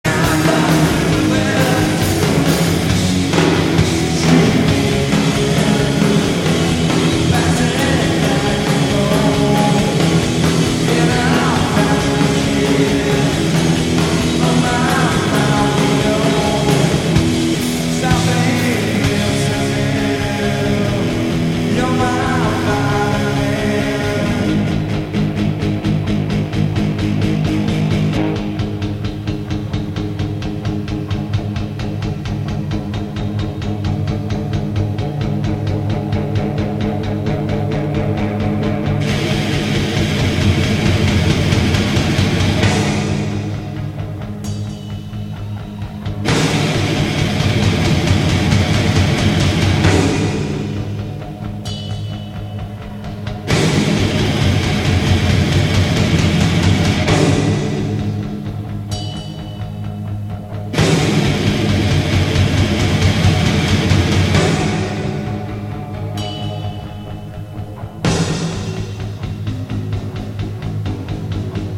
The Palace (Rock For Choice Benefit), Hollywood, CA, US